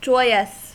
Ääntäminen
Synonyymit ecstatic sunny Ääntäminen US Tuntematon aksentti: IPA : /d͡ʒɔɪəs/ Haettu sana löytyi näillä lähdekielillä: englanti Määritelmät Adjektiivit Full of joy ; happy .